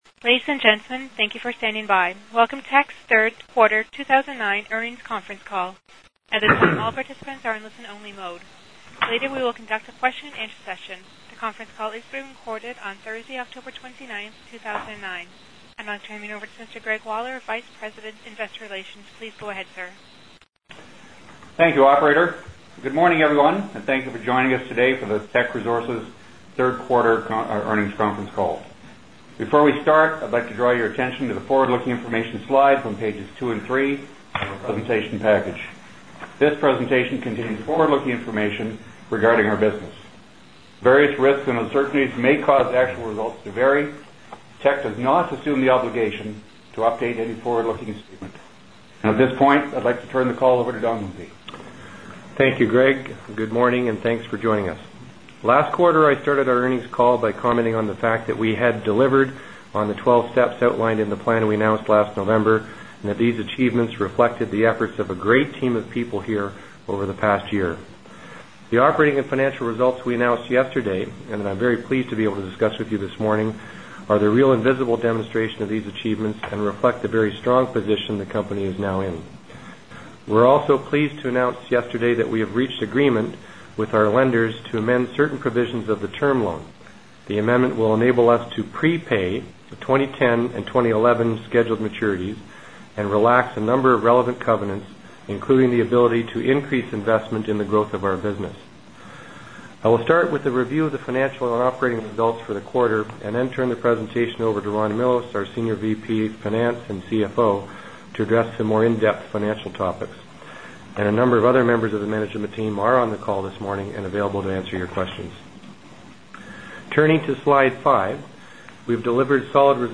Q3 2009 Financial Report [PDF - 0.49 MB] Q3 2009 Financial Report Conference Call Transcript [PDF - 0.43 MB] Q3 2009 Financial Report Conference Call Audio File [ - 24.14 MB] Q3 2009 Financial Report Conference Call Presentation Slides [PDF - 1.11 MB]